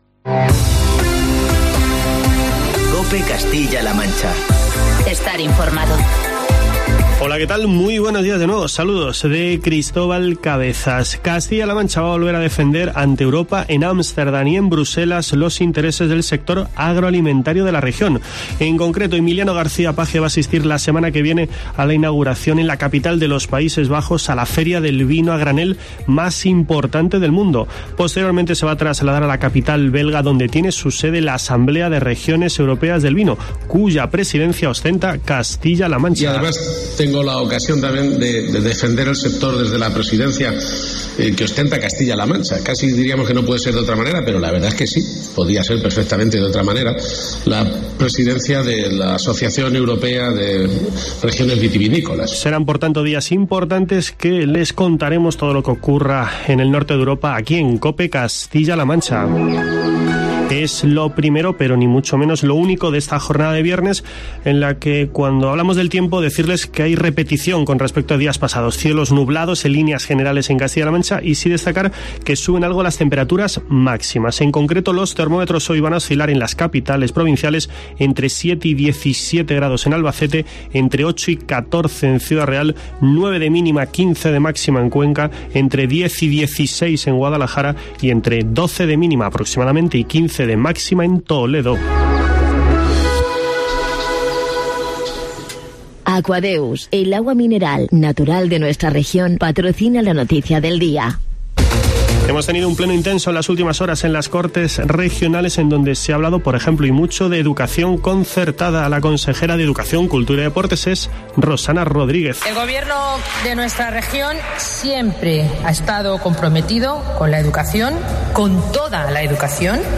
Escucha en la parte superior de esta noticia los informativos matinales de COPE Castilla-La Mancha y COPE Toledo de este viernes, 29 de noviembre de 2019.